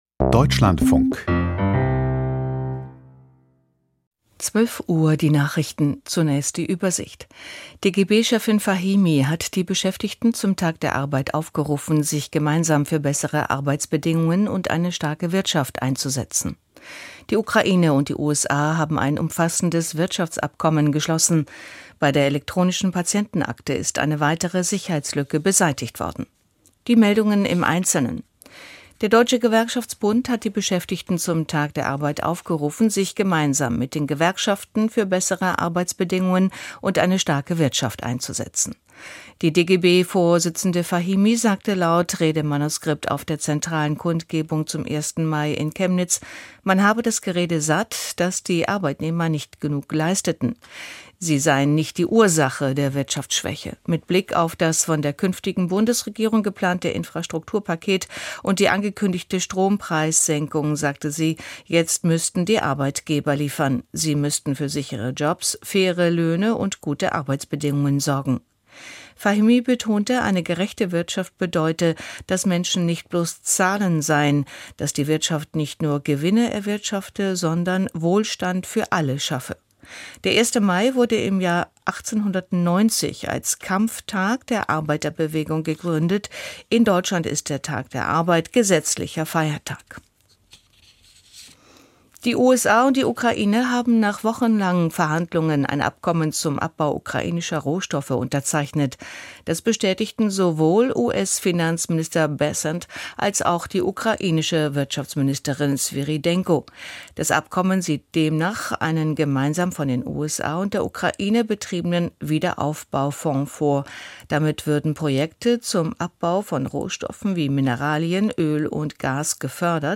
Die Deutschlandfunk-Nachrichten vom 01.05.2025, 12:00 Uhr